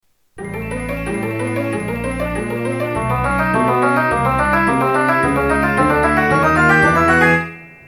Open Treasure Box